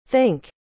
Xát răng /TH/ | comic Anh Việt
think.mp3